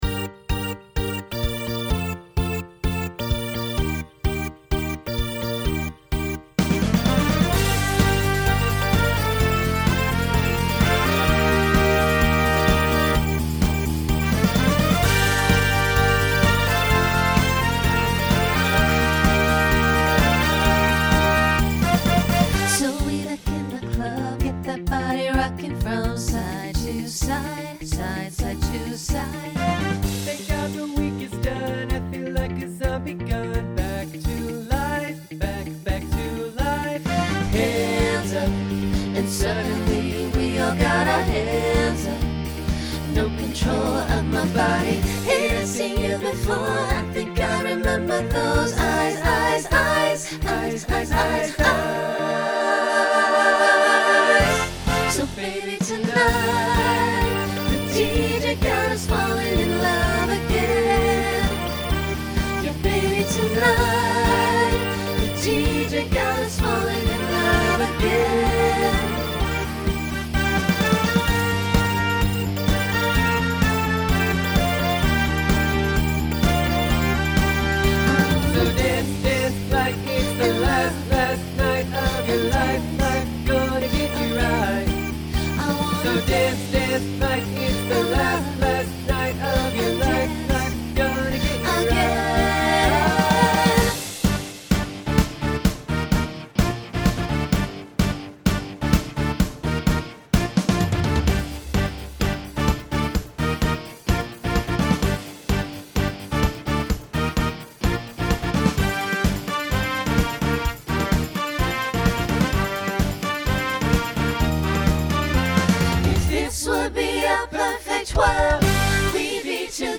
Genre Pop/Dance
Voicing SATB